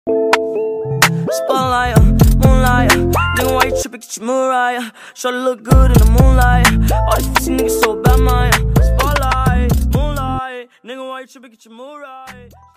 Play, download and share creatorcode original sound button!!!!
free-twitchtv-follower-alert-18-youtubemp3free.mp3